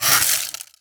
ice_spell_freeze_small_01.wav